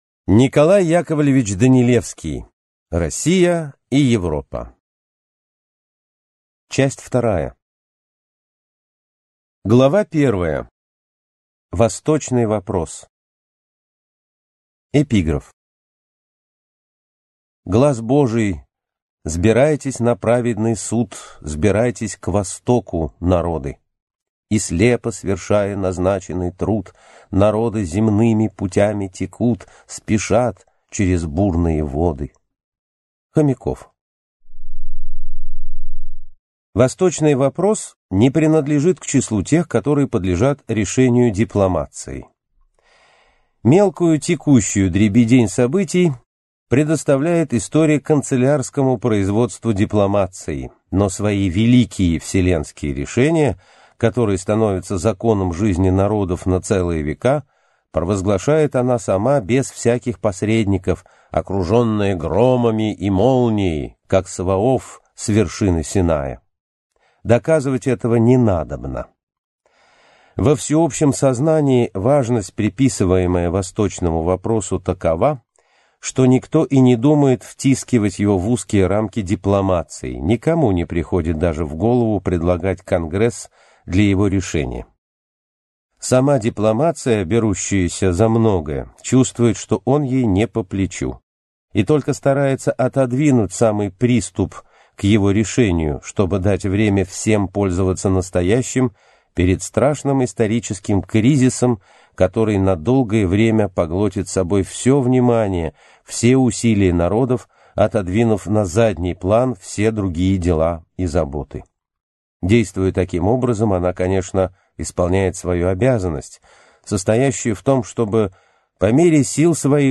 Аудиокнига Россия и Европа. Часть 2 | Библиотека аудиокниг